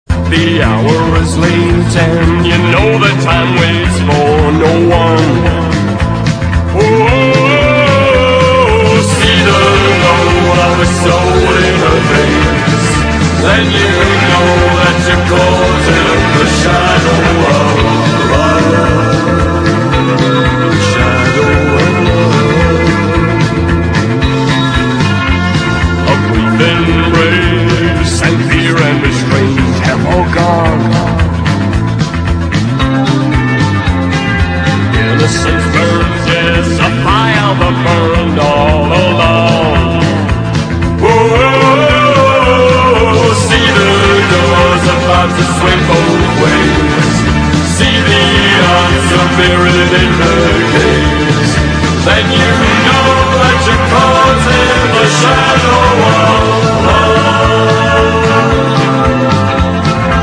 ROCK / PUNK / 80'S～ / 70'S PUNK / NEW WAVE (UK)